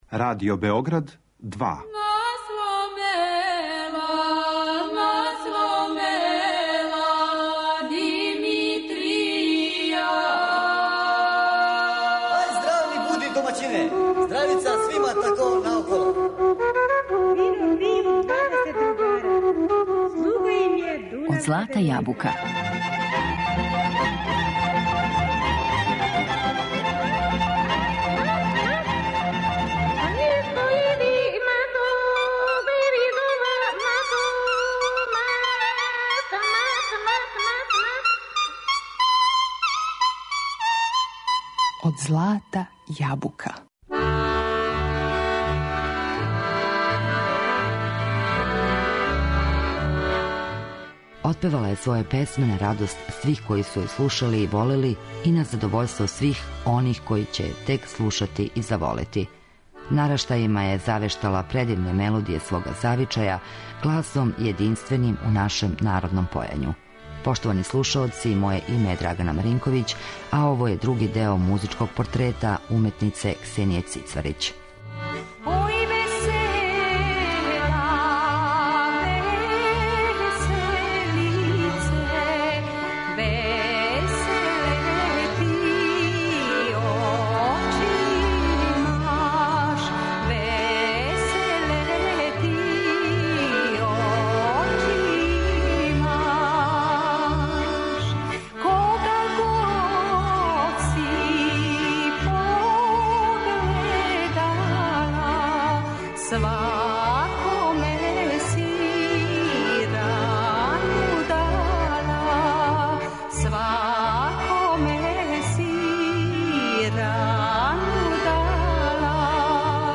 Нараштајима је завештала предивне мелодије свога завичаја, гласом јединственим у нашем народном појању.